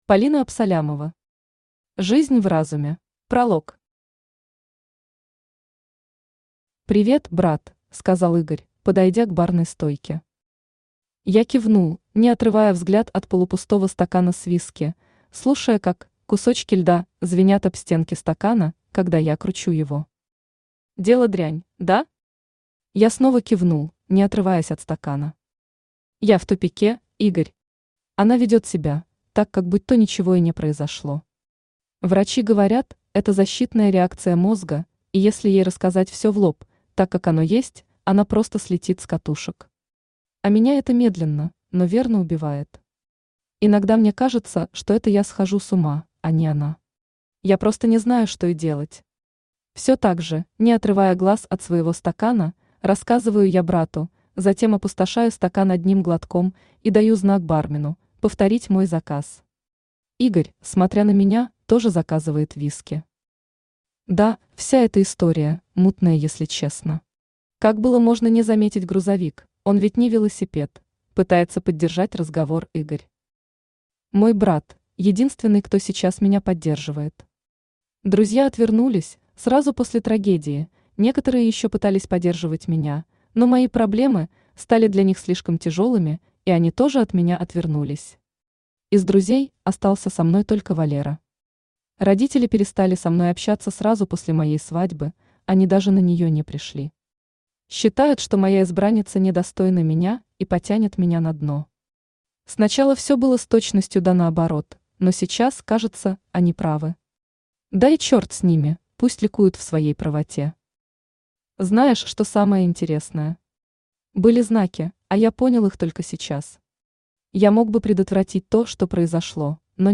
Аудиокнига Жизнь в разуме | Библиотека аудиокниг
Aудиокнига Жизнь в разуме Автор Полина Александровна Абсалямова Читает аудиокнигу Авточтец ЛитРес.